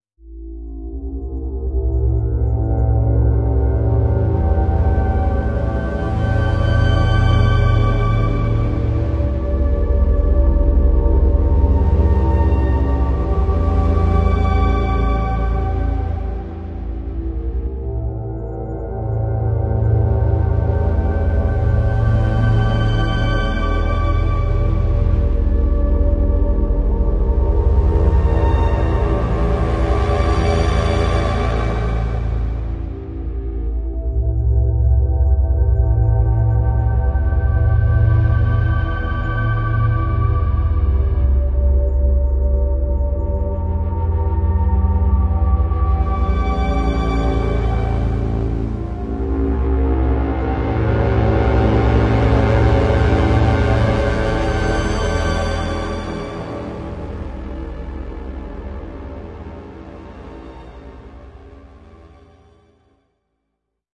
描述：一种紧张的气氛。
标签： 环境 气氛 电影 黑暗 电子 音乐 加工 科幻 合成器
声道立体声